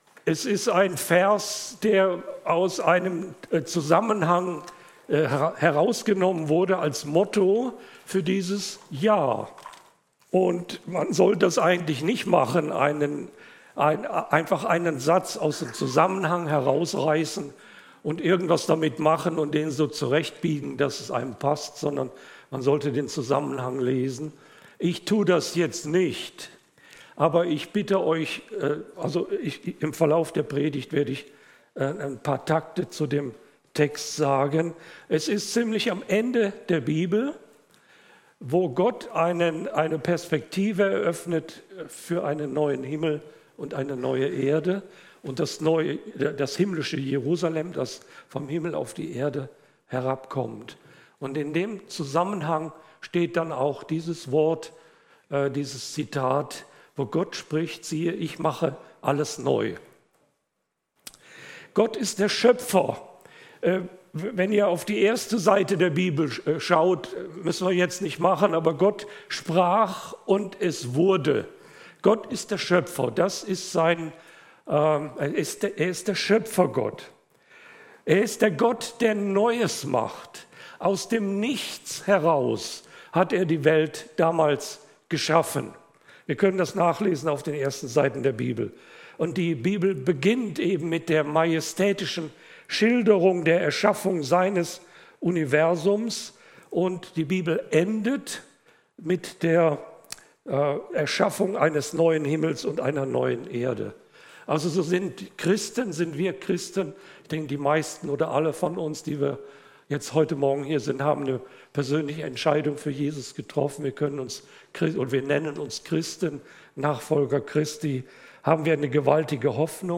Erweiterte Suche Siehe ich mache alles neu! vor 3 Monaten 34 Minuten 0 0 0 0 0 0 Podcast Podcaster Predigten D13 Hier hörst du die Predigten aus unserer Gemeinde.